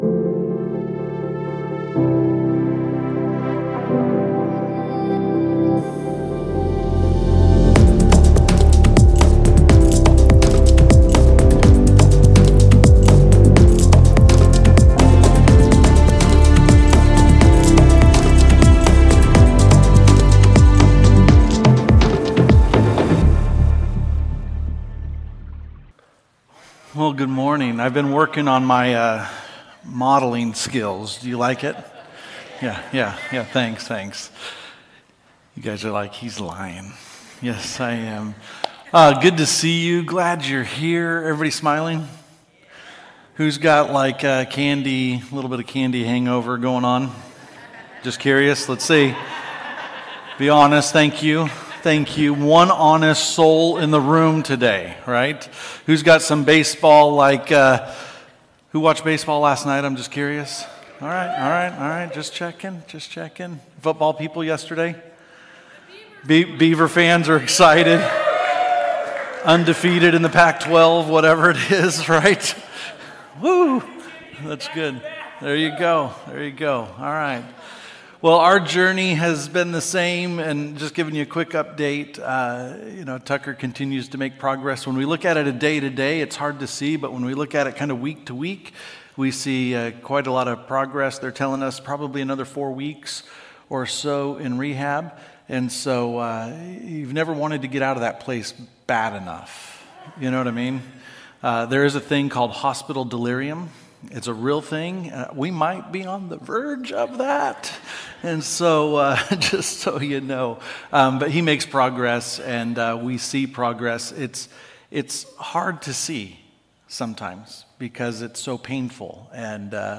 Sermons | Mountainview Christian Church